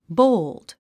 発音 bóuld ボーゥド